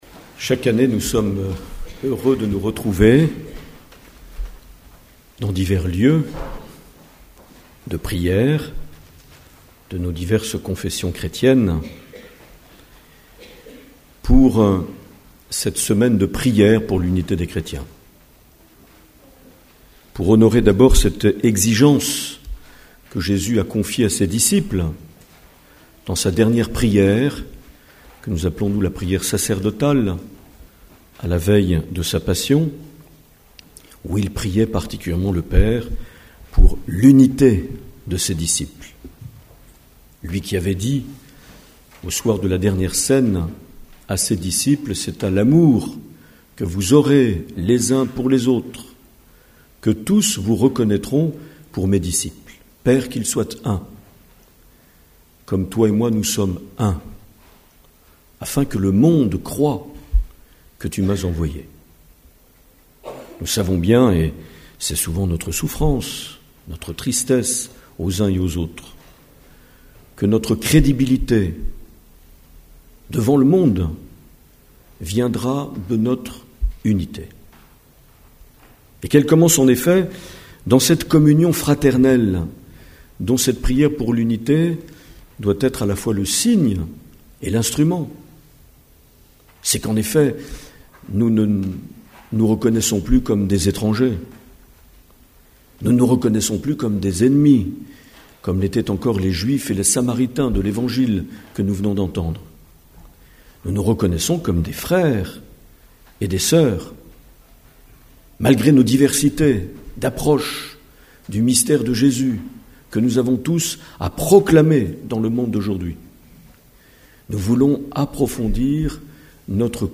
20 janvier 2015 - Temple de Bayonne - Célébration oecuménique
Une émission présentée par Monseigneur Marc Aillet